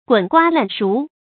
注音：ㄍㄨㄣˇ ㄍㄨㄚ ㄌㄢˋ ㄕㄨˊ
滾瓜爛熟的讀法